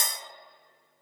Index of /musicradar/layering-samples/Drum_Bits/Verb_Tails